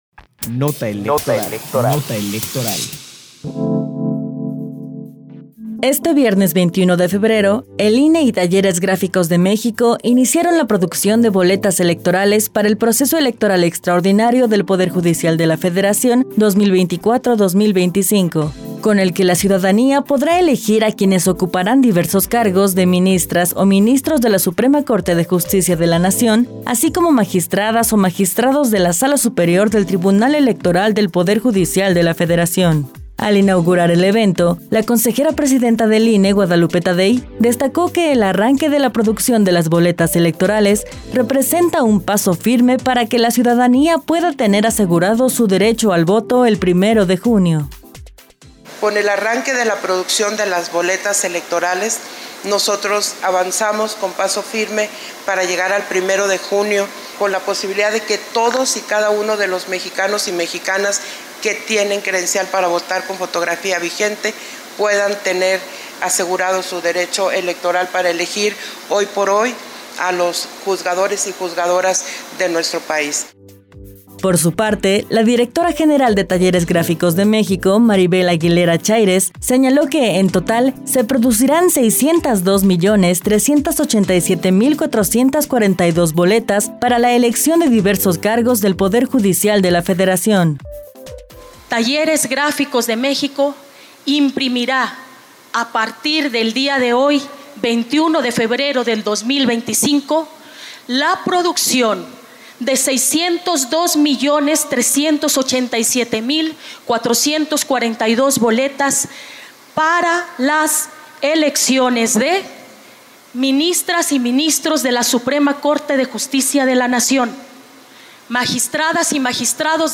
Nota de audio sobre el inicio de la producción de boletas electorales para el Proceso Electoral Extraordinario del Poder Judicial de la Federación 2024 – 2025